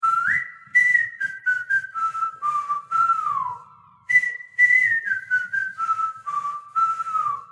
VOX_GCK_125_vocal_whistle_wet_ominous_Em